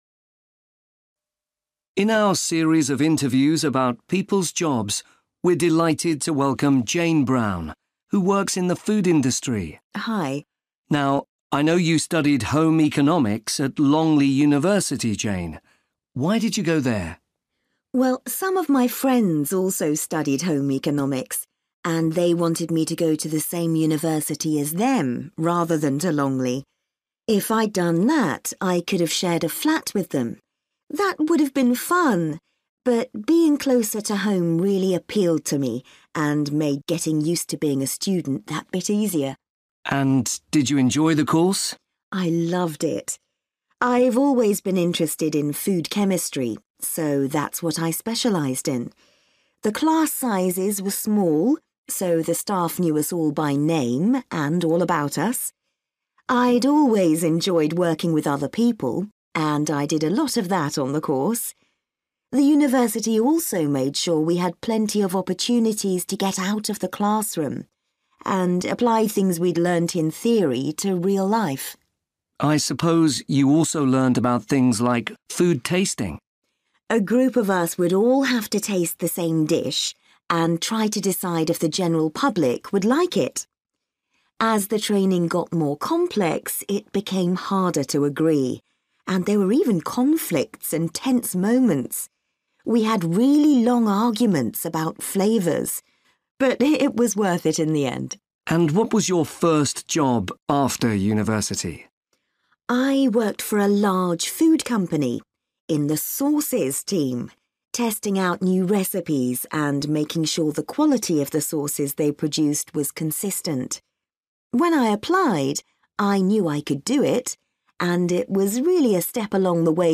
You will hear part of a radio interview